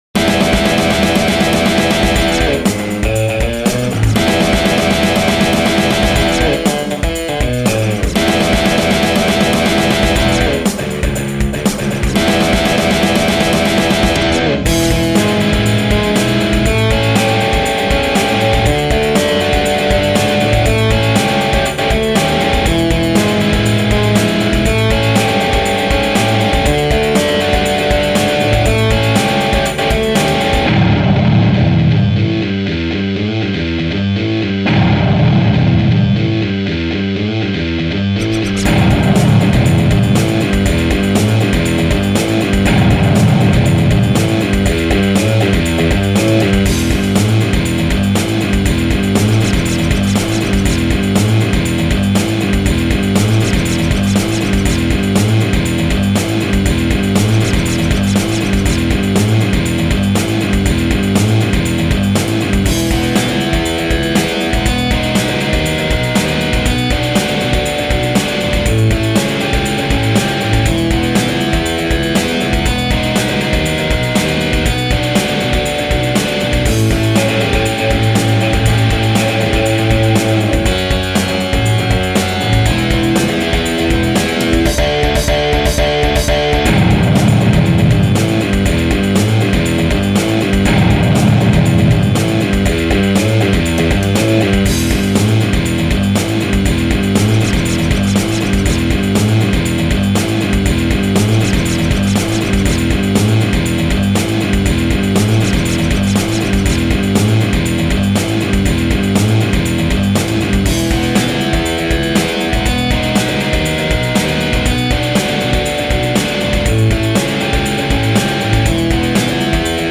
●AmpRoomRock●StudiumRock●Dist2VX